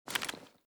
mossberg_open.ogg.bak